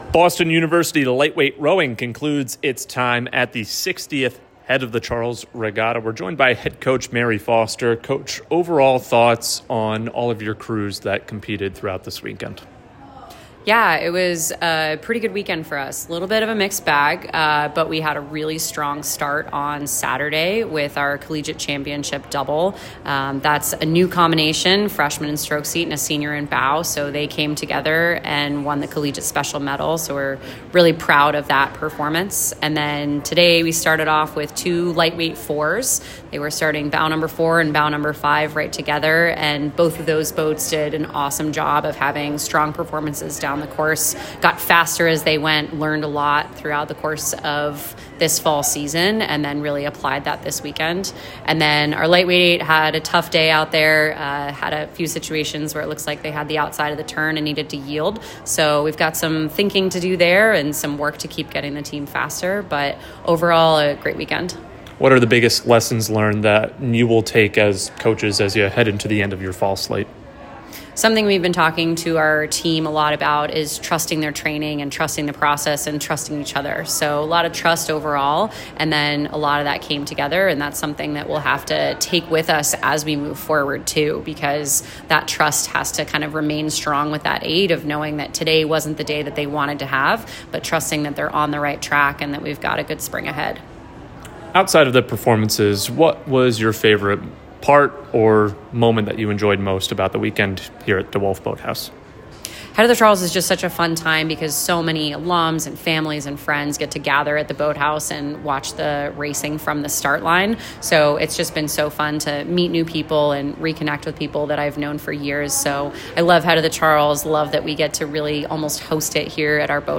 Head of the Charles Interview